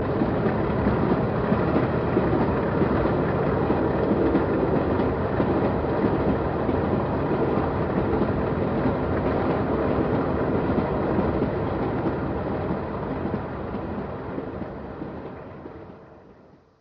40.166 Leaves Perth and runs through Moncrieff Tunnel on the 11.17